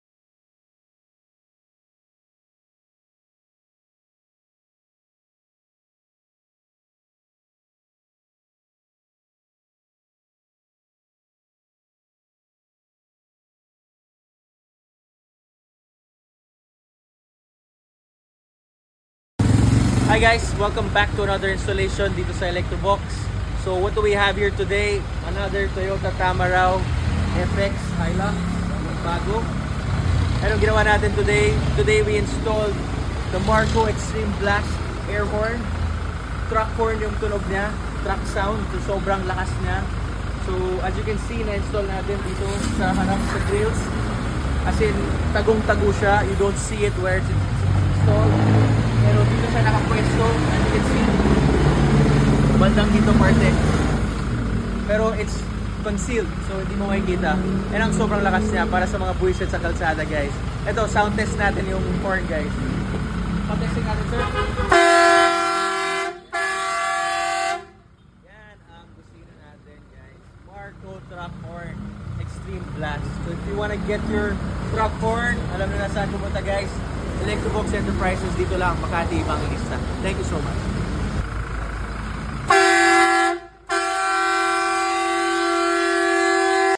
Toyota tamaraw hilux 2025 upgraded Horn with Marco Xtreme Blast Air Horn (Truck Sound)